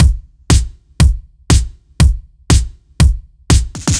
Index of /musicradar/french-house-chillout-samples/120bpm/Beats
FHC_BeatD_120-01_KickSnare.wav